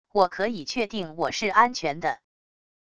我可以确定我是安全的wav音频生成系统WAV Audio Player